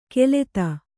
♪ keleta